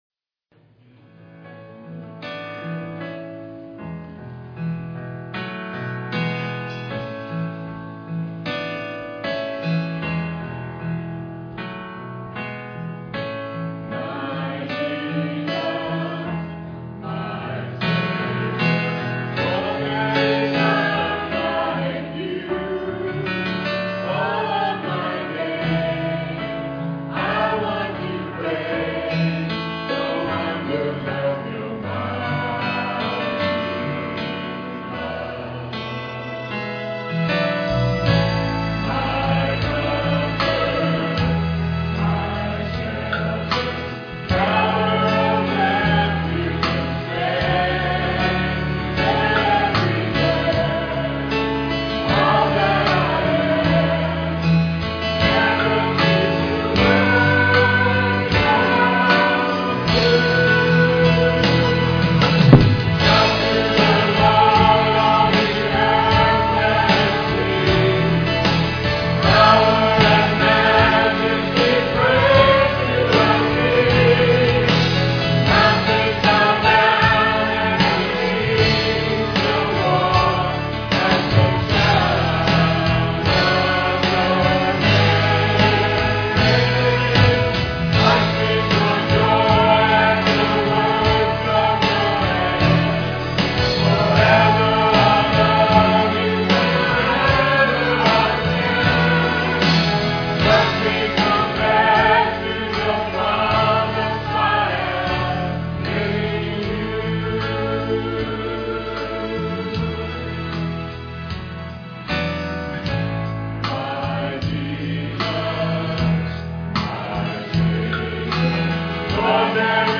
Piano and organ offertory